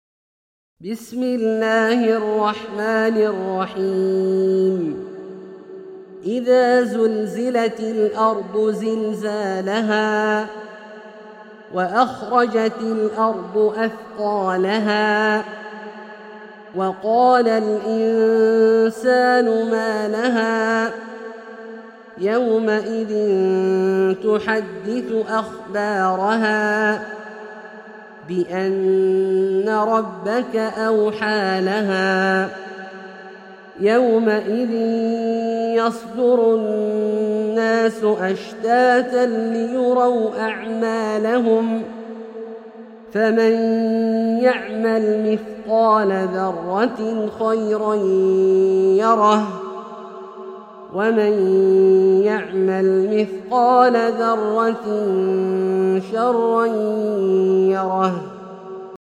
سورة الزلزلة - برواية الدوري عن أبي عمرو البصري > مصحف برواية الدوري عن أبي عمرو البصري > المصحف - تلاوات عبدالله الجهني